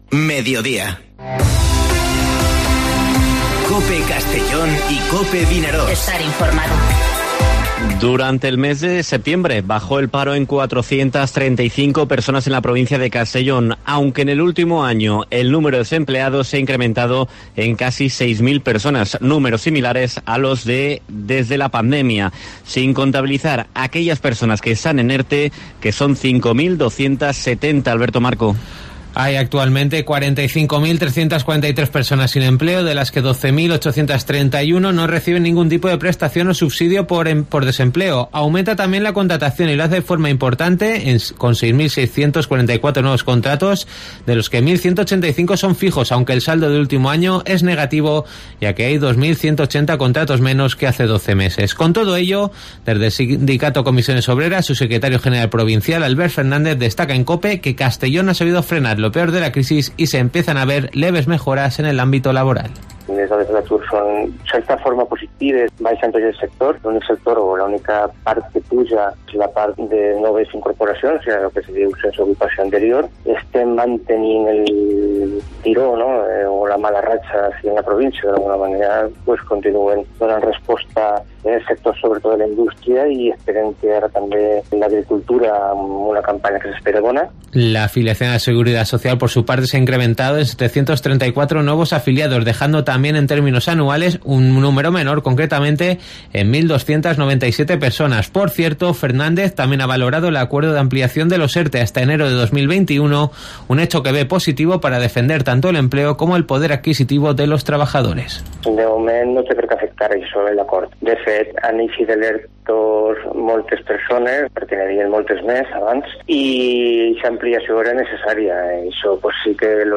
Informativo Mediodía COPE en la provincia de Castellón (02/10/2020)